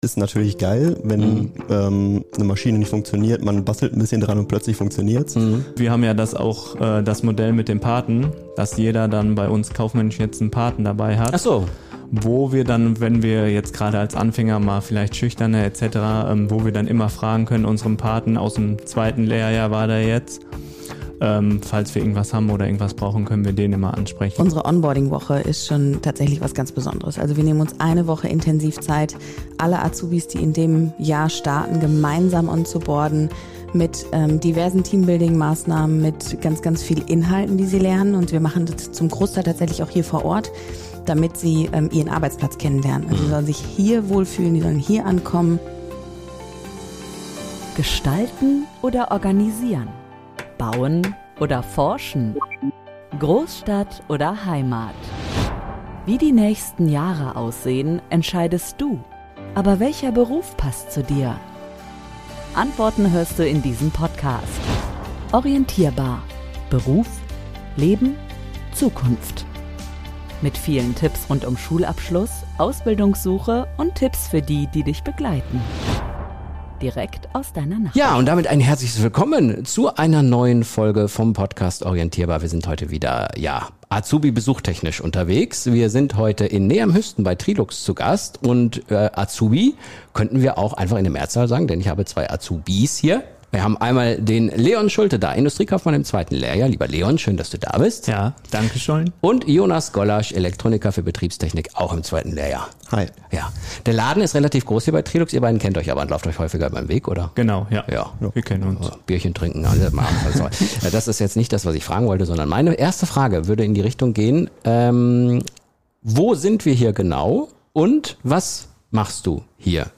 Beschreibung vor 1 Jahr In der neuesten Folge des Podcasts "Orientierbar" hatten wir das Vergnügen, bei Trilux im sauerländischen Neheim zu Gast zu sein.